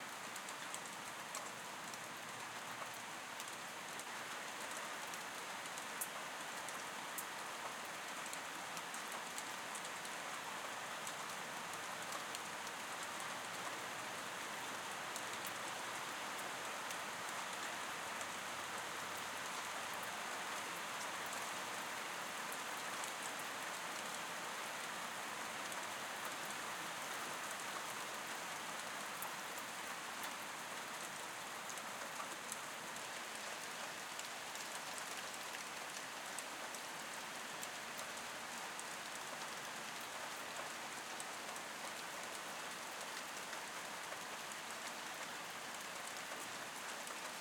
ambient-rain-light-washed-out-stereo-ms.ogg